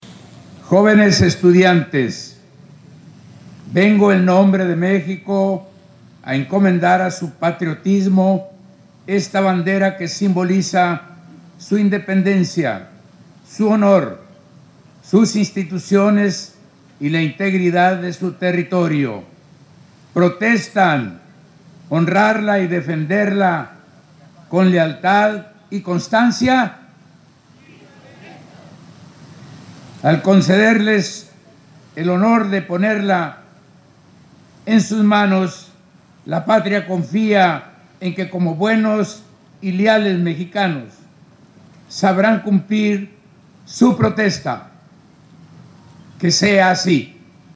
Acompañado por el comandante de la Novena Zona Militar, general de Brigada, Santos Gerardo Soto, y por demás autoridades civiles y militares, el gobernador Rocha presidió este evento cívico en la explanada de Palacio de Gobierno, para honrar a nuestra enseña nacional y máximo símbolo de identidad y patriotismo de las y los mexicanos.
Luego de abanderar a siete escoltas estudiantiles, en representación de los 34 planteles educativos que en este acto recibieron un nuevo lábaro patrio, 15 de ellas de educación secundaria, y las restantes 19 de educación media superior, el mandatario estatal se dirigió a las alumnas y alumnos para hacer el juramento.